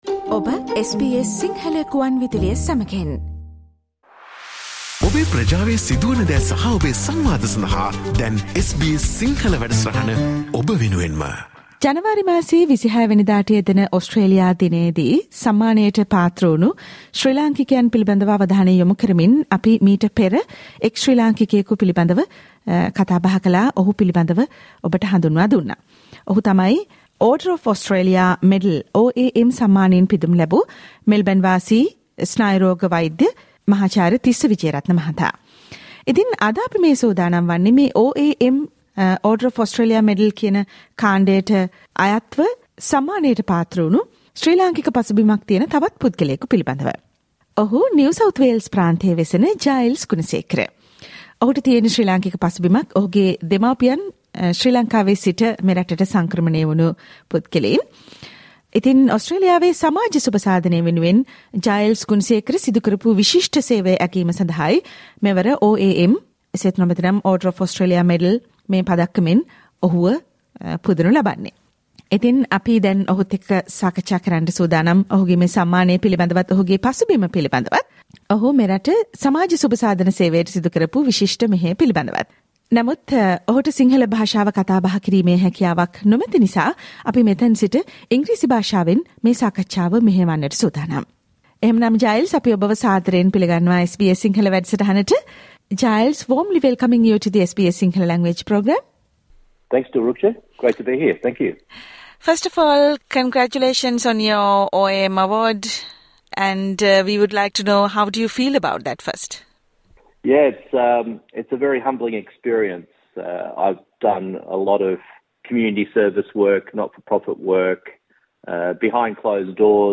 SBS Sinhala Radio discussion